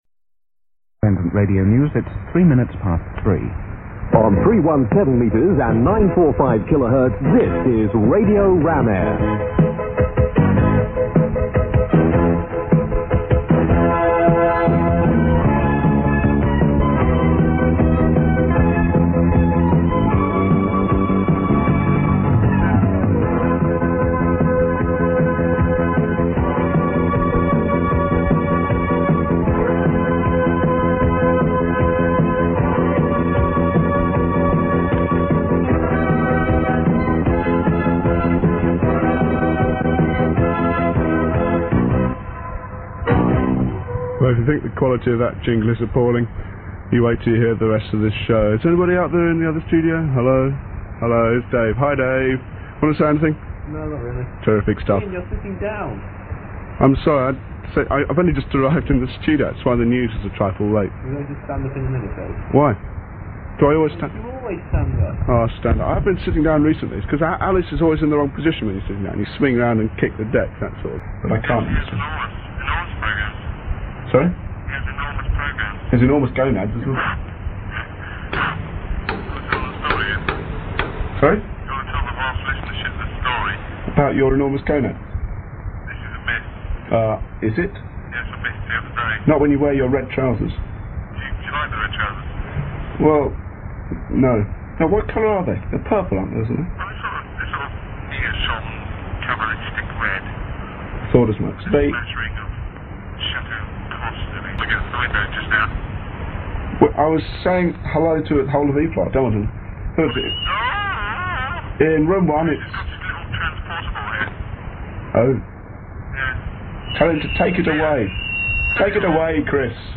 Radio Ramair, Bradford.
I was in Halls of Residence at the time, obviously too lazy to go to the studio.